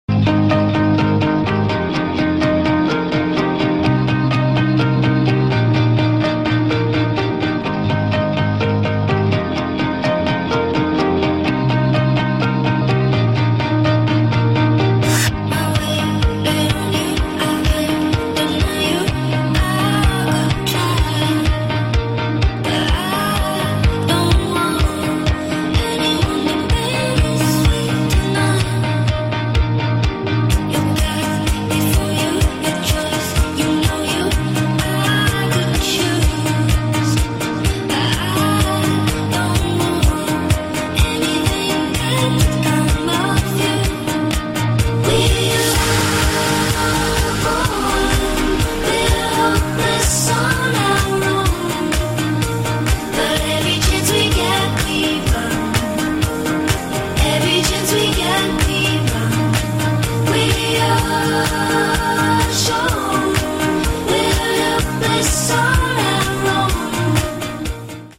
• Качество: 128, Stereo
гитара
ритмичные
женский вокал
спокойные
house